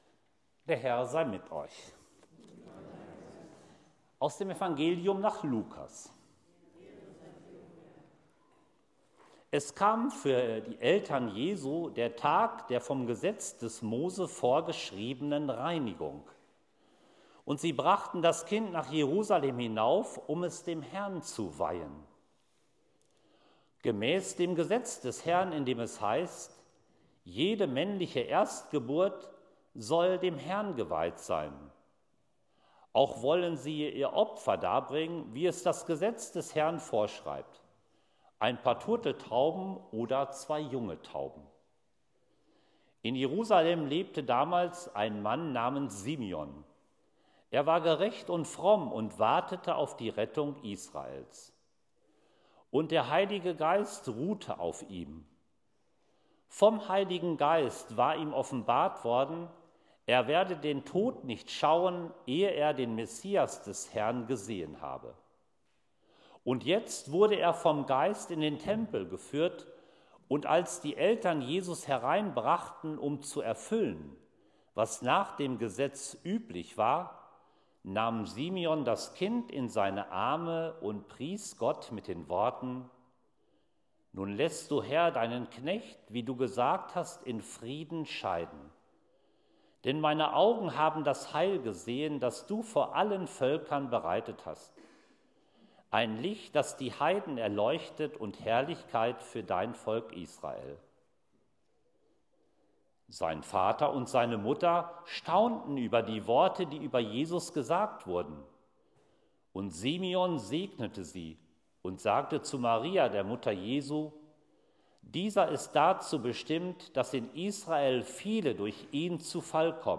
Predigt-Wirkung-der-Weihnachtsbotschaft_01.mp3